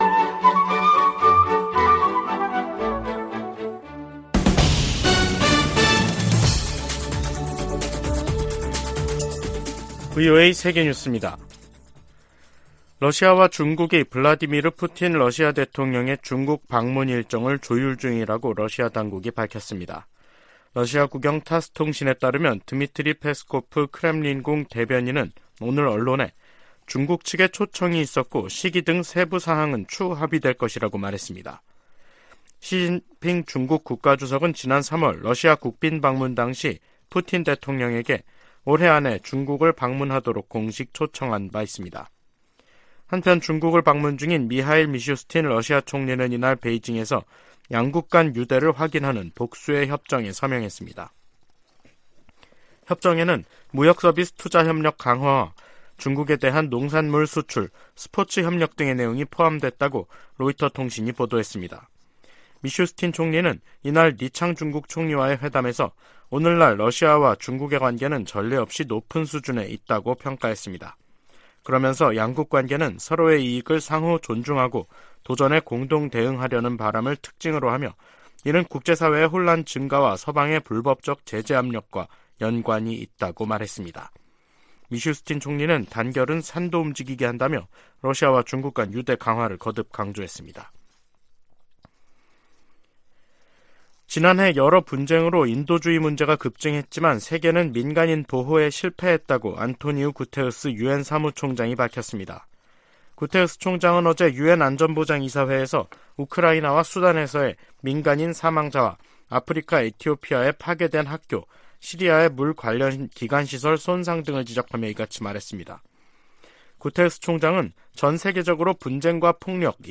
VOA 한국어 간판 뉴스 프로그램 '뉴스 투데이', 2023년 5월 24일 2부 방송입니다. 미 재무부가 북한의 불법 무기 프로그램 개발에 필요한 자금 조달과 사이버 활동에 관여한 기관 4곳과 개인 1명을 제재했습니다. 북한이 27년 연속 미국의 테러 방지 노력에 협조하지 않는 나라로 지정됐습니다. 북한 핵역량 고도화로 한국에서 자체 핵무장론까지 나온 상황은 워싱턴에 경종을 울린다고 전직 백악관 고위 관리들이 말했습니다.